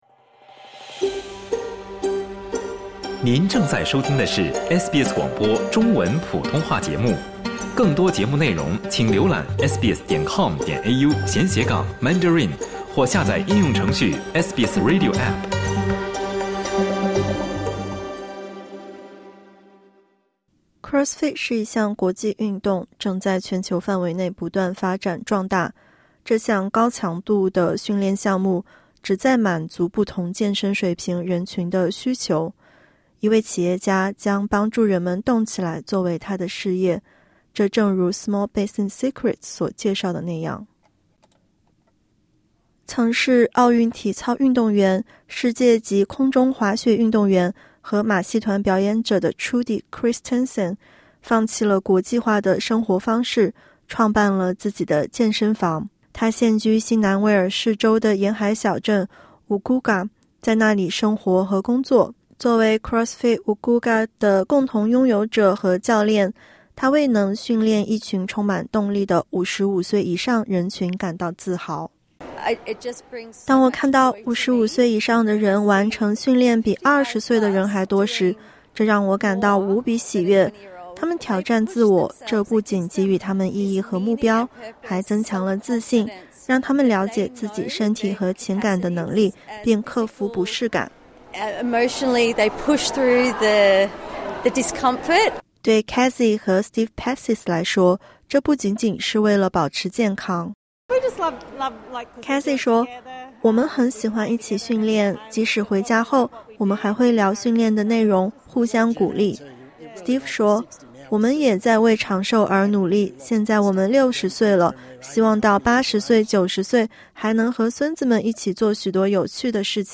一位企业家将帮助人们积极锻炼作为自己的事业 (点击音频收听详细报道)。